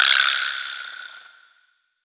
Bp Guiro.wav